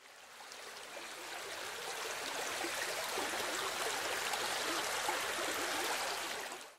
Download River sound effect for free.
River